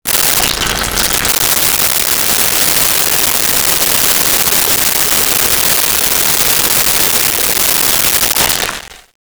Urinating 1
urinating-1.wav